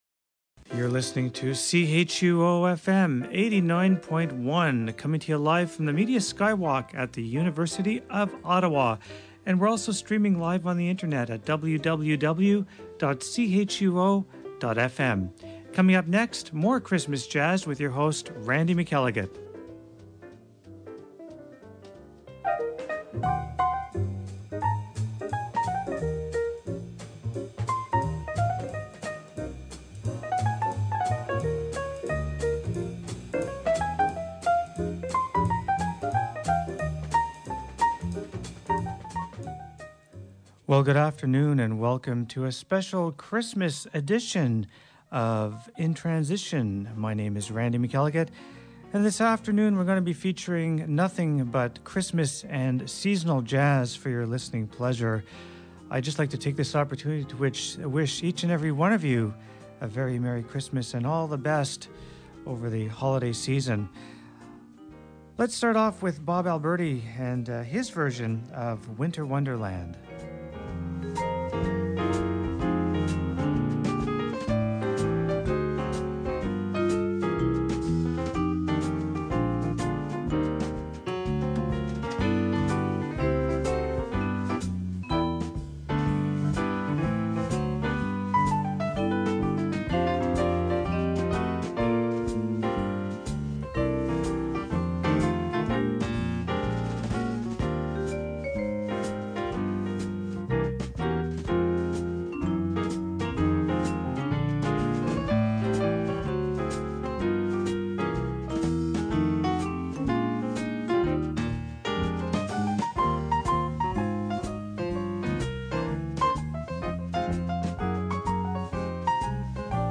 2003-12-In-Transition-December-21 Christmas jazz is featured on this episode.